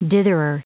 Prononciation du mot ditherer en anglais (fichier audio)
Prononciation du mot : ditherer
ditherer.wav